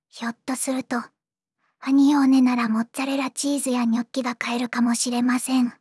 voicevox-voice-corpus
voicevox-voice-corpus / ROHAN-corpus /ずんだもん_ヒソヒソ /ROHAN4600_0041.wav